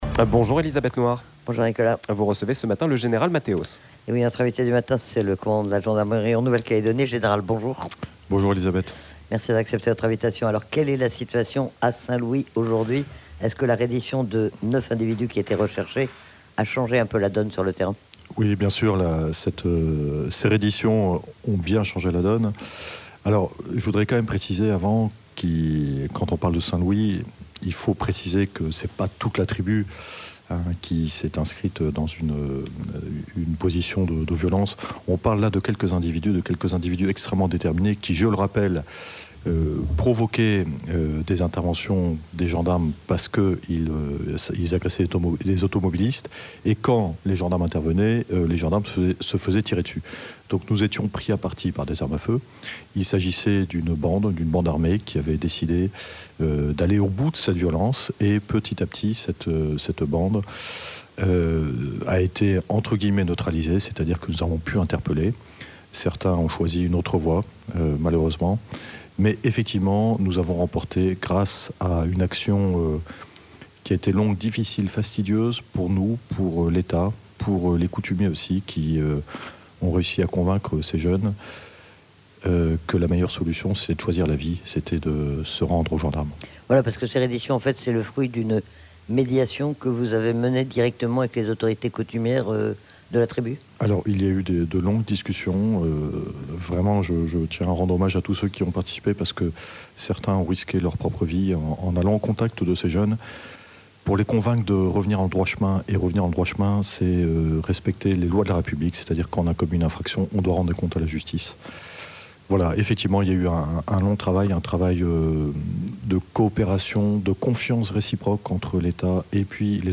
C'est le commandant de la gendarmerie en Nouvelle-Calédonie, le général Matthéos, qui était notre invité du matin. Nous l'avons interrogé sur la situation à Saint Louis après la reddition de plusieurs individus recherchés et, plus globalement, sur la situation sur le terrain, près de 4 mois et demi après le début des émeutes.